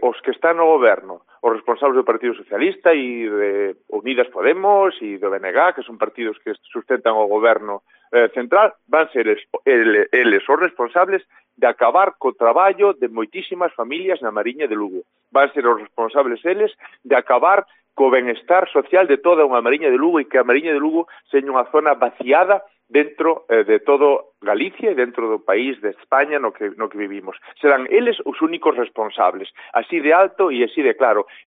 DECLARACIONES de Alfonso Villares